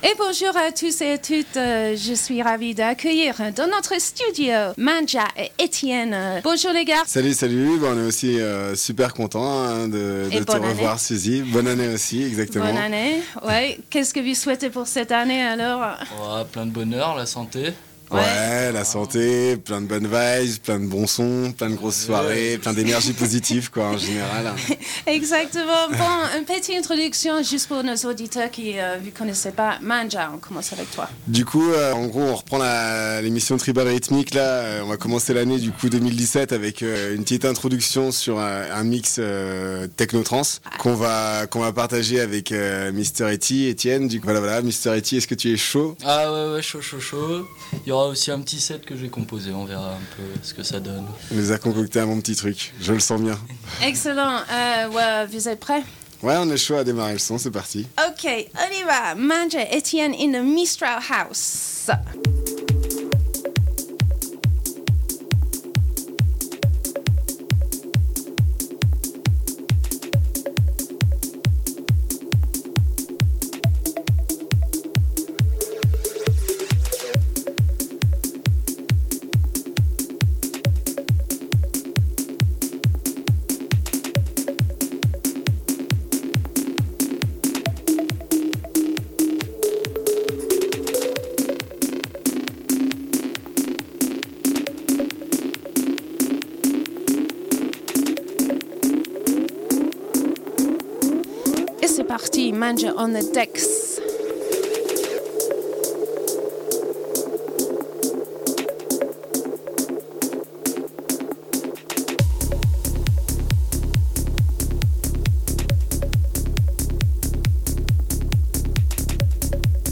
techno-trance